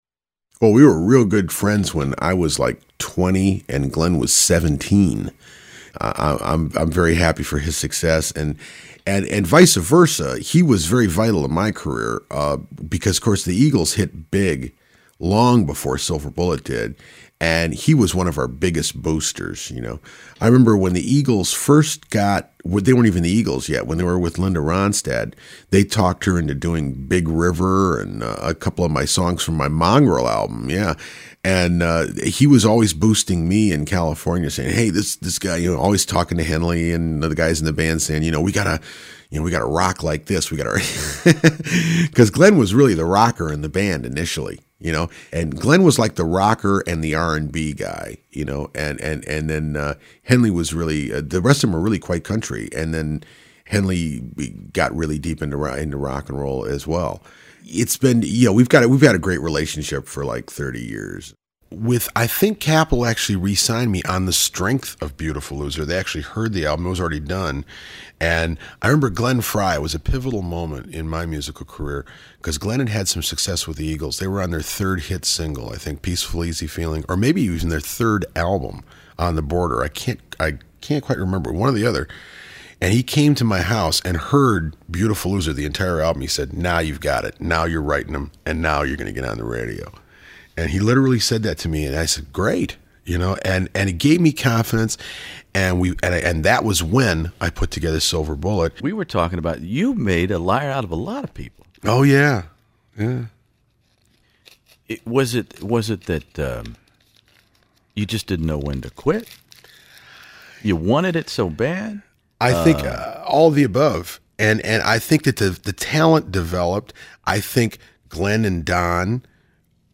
As you can hear in my interview, Bob mentored the inexperienced Frey until Glenn headed to the West Coast, met another unknown musician from Gilmer Texas named Don Henley, united behind Stone Ponies singer Linda Ronstadt as her tour band, before taking wing shortly thereafter as The Eagles and flying into music history.